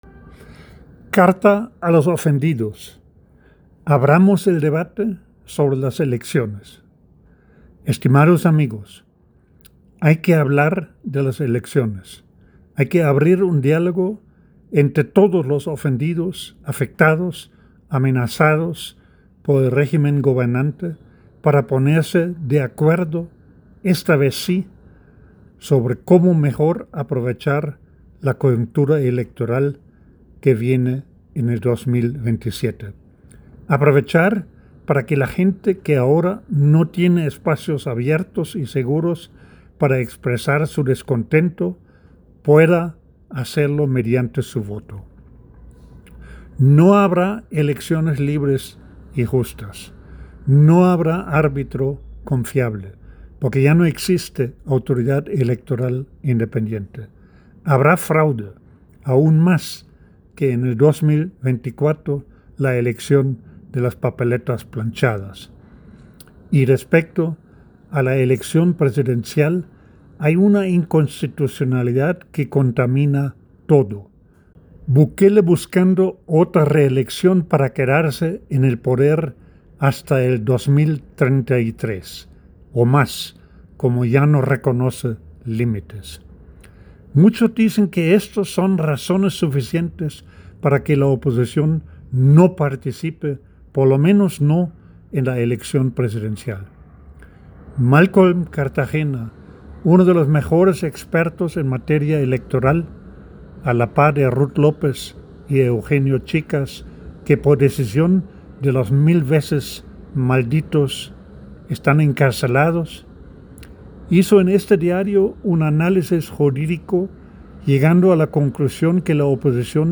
En la voz del autor